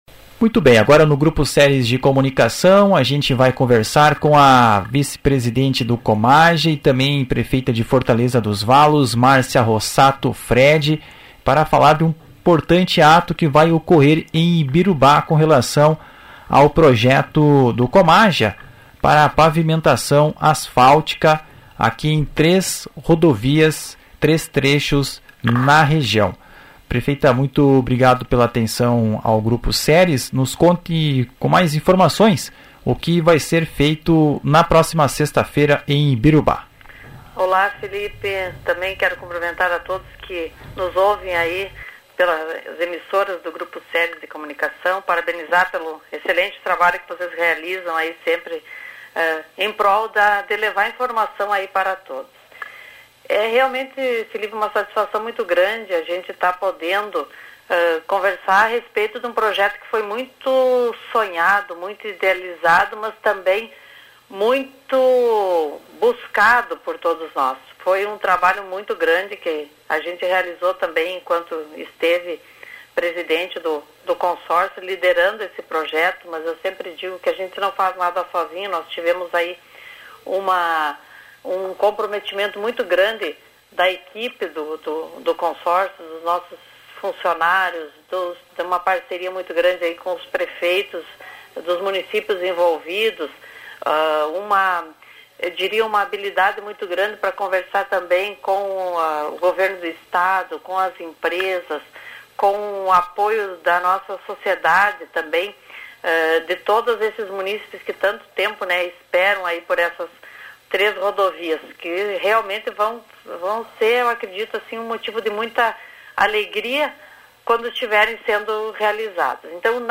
O Presidente do COMAJA e também Prefeito de Ibirubá, Abel Grave, em entrevista ao Grupo Ceres de Comunicação expressa sua perspectiva a respeito da assinatura.